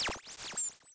transistor.wav